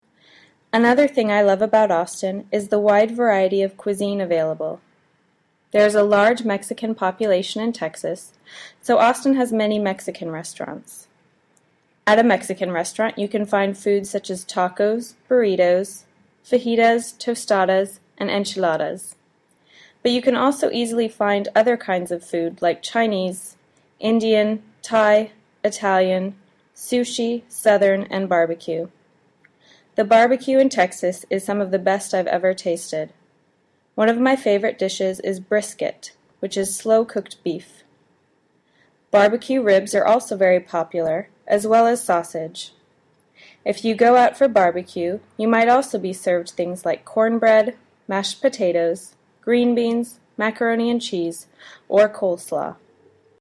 Accent
Américain